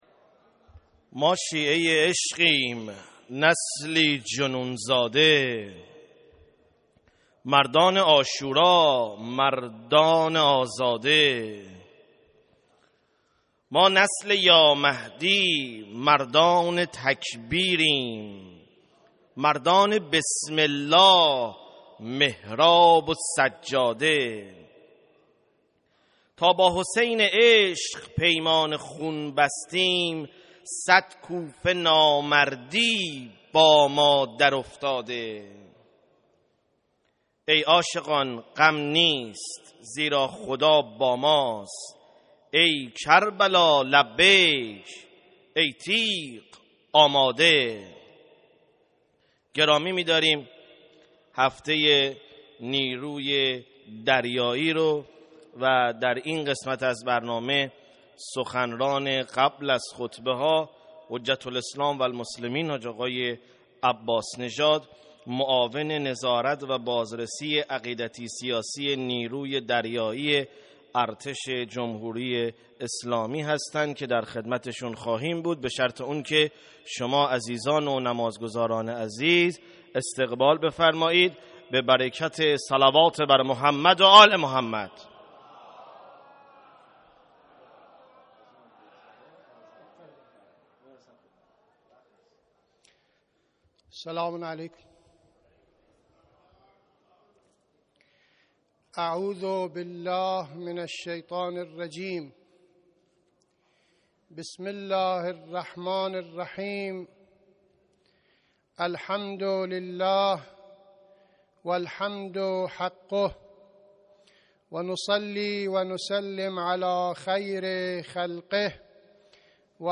92.9.8-سخنران-نداجا.mp3